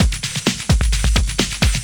Index of /90_sSampleCDs/Ueberschall - Techno Trance Essentials/02-29 DRUMLOOPS/TE20-24.LOOP-ADDON+HIHAT/TE20.LOOP-ADDON2